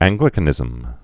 (ăngglĭ-kə-nĭzəm)